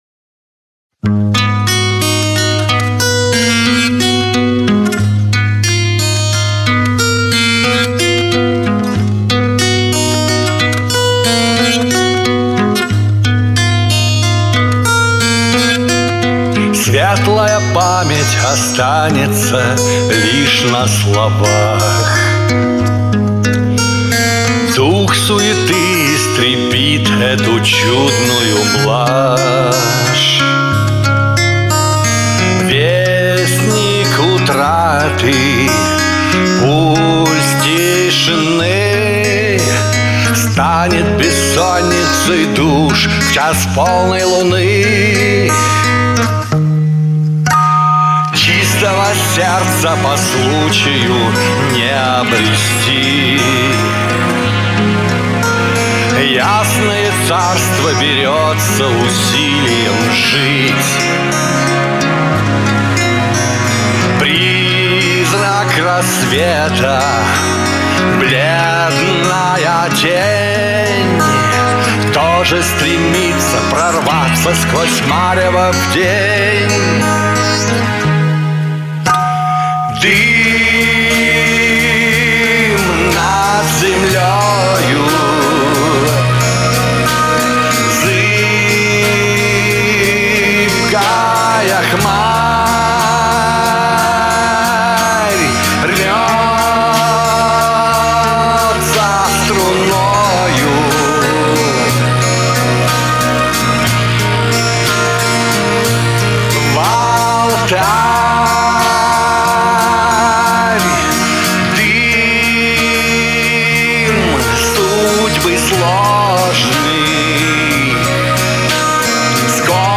Но здесь как-то грязновато звучишь. Особенно в припевах.